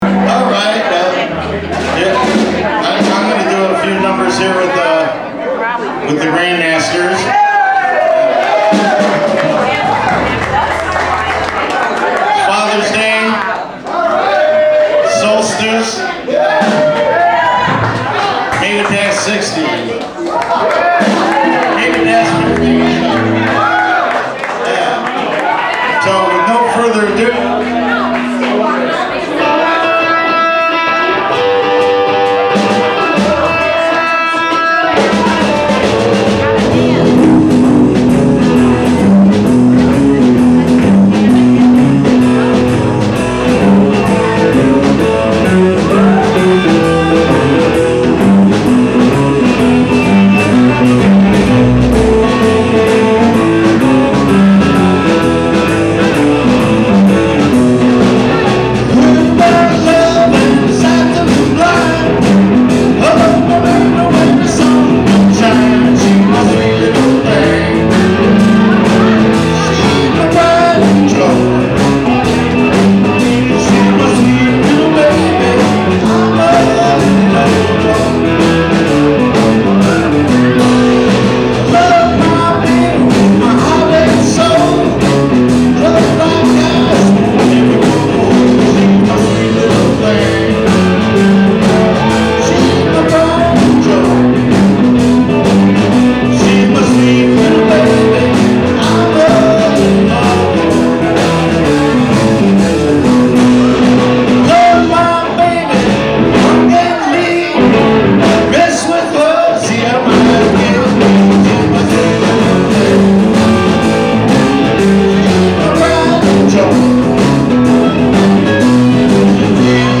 from a camcorder on a tripod
Live at Boo's